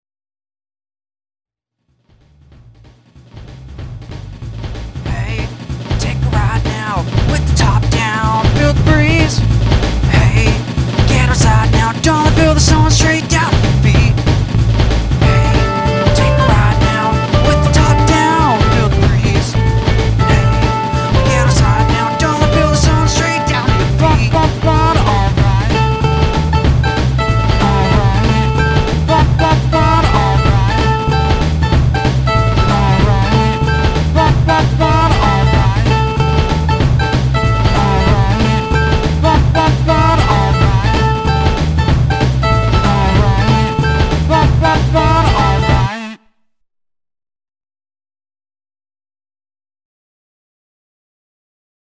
Fab-ish